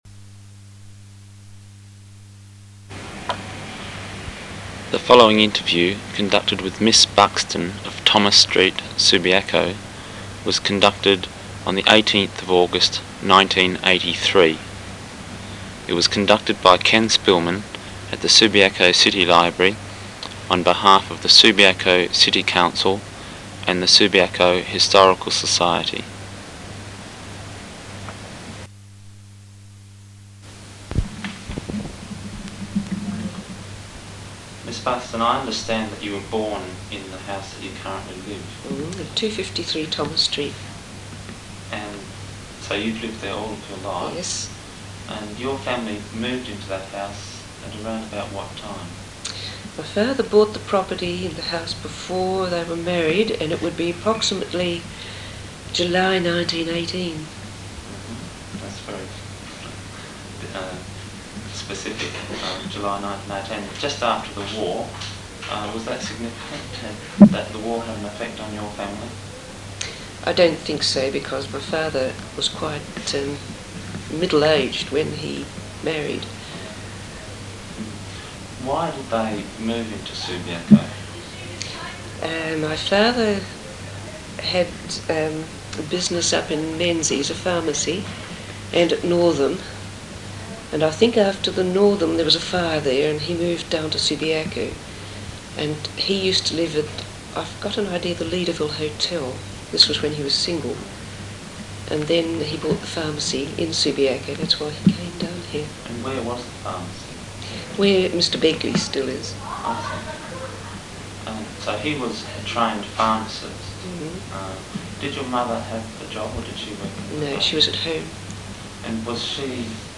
Copied to clipboard Subcollections Oral History Overview Summary
The original tape is in the Battye Library.